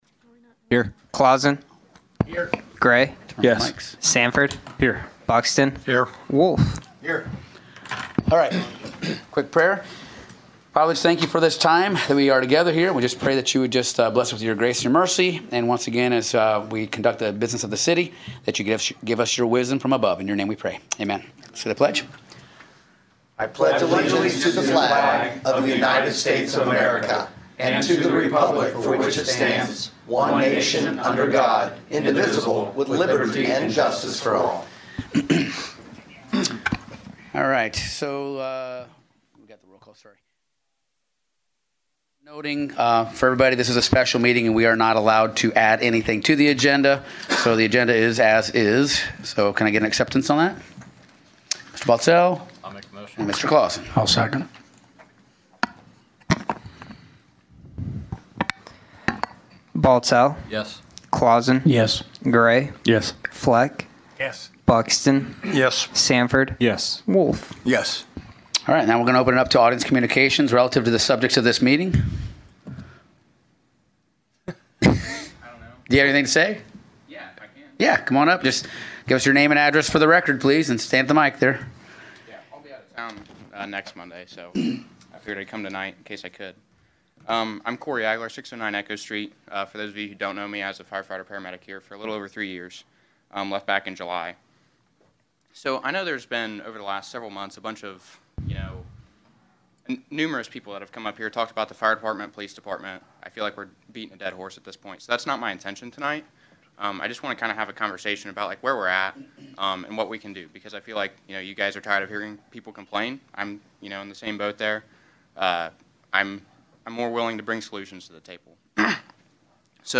The Celina City Council held a special meeting Monday Night December 9th.
Location: City Council Chambers